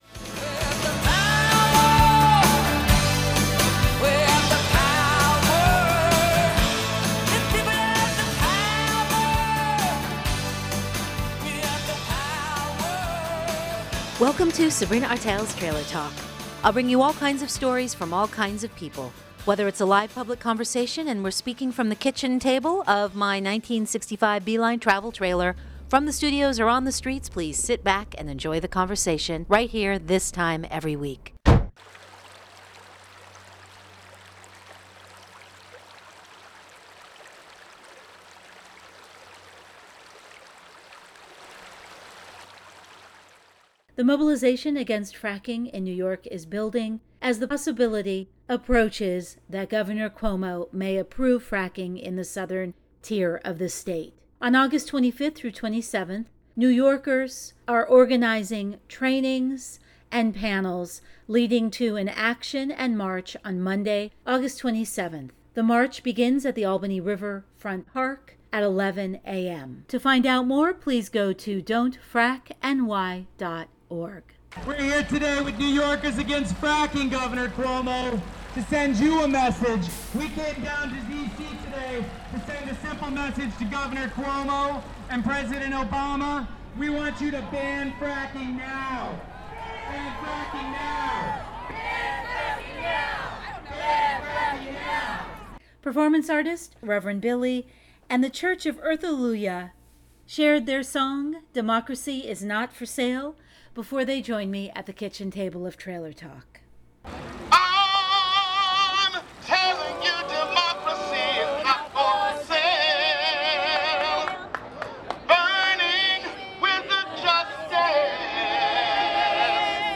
This Road session shares the voices and songs of the growing movement to stop fracking in New York. As the possible approval of fracking by Governor Cuomo in the Southern Tier approaches the campaign to resist fracking is entering a new stage of actions, trainings and mobilization.